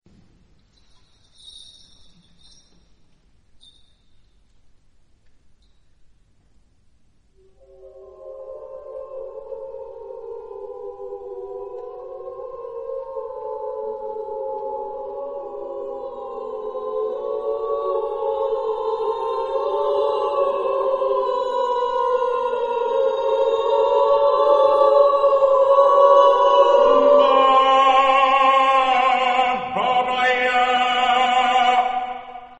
Género/Estilo/Forma: Obra coral ; Profano
Tipo de formación coral: SSAATTBB  (8 voces Coro mixto )
Instrumentos: Percusión (1)
Tonalidad : libre